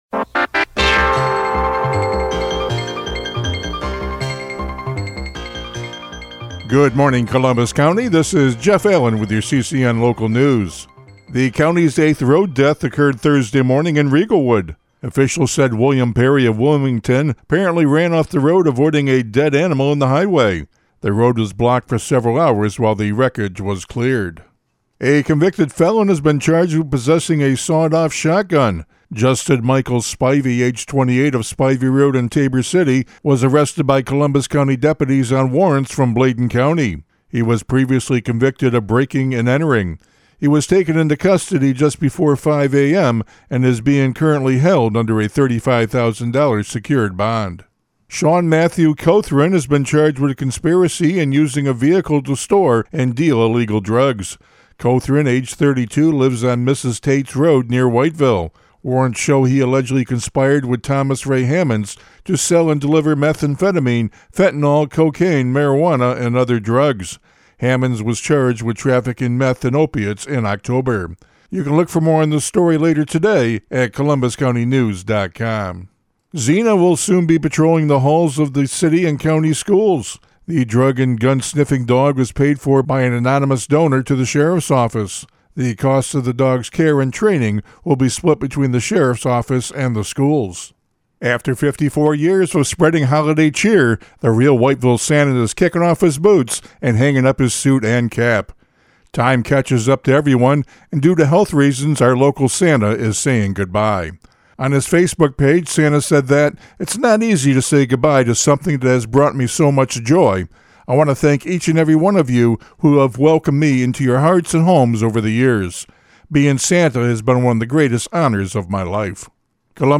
CCN Radio News — Morning Report for November 14, 2025